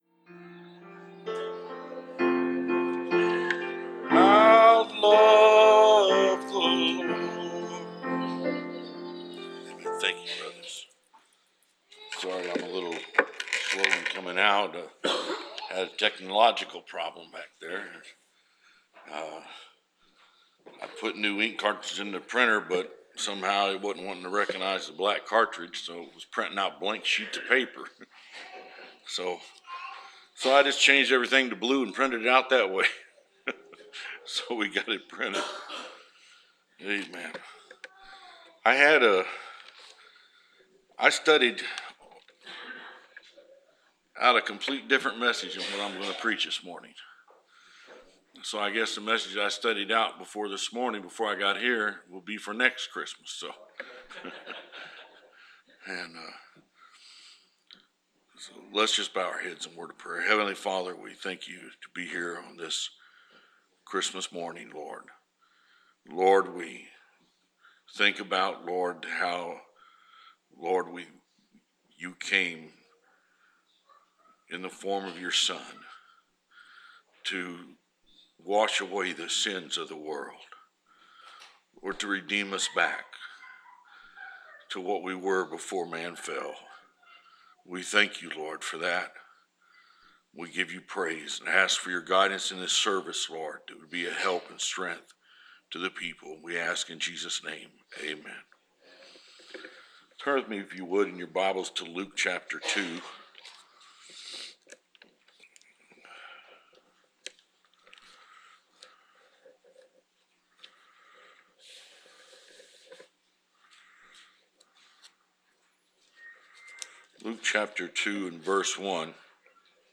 Preached December 25, 2016